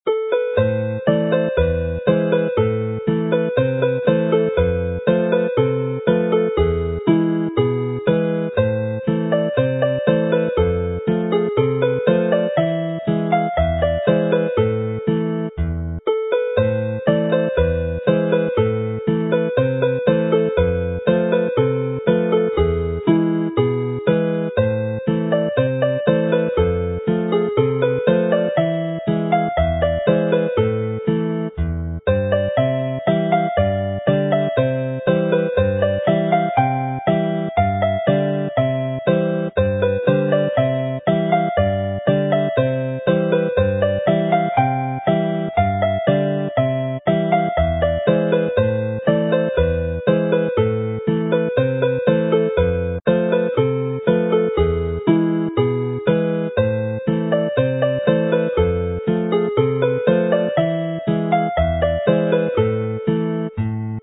Dŵr Glân - gyda chordiau
Clean, Clear Water - with chords